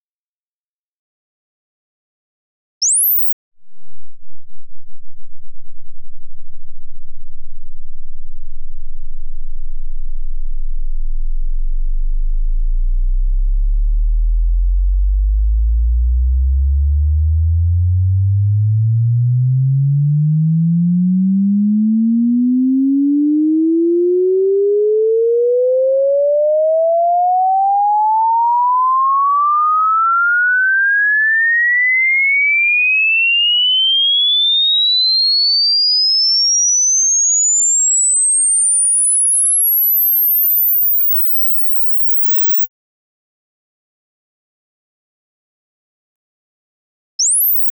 For these measurements, I played 44.1 kHz sweep in REW and sampled it at 96 kHz with the Focusrite.
sweep file.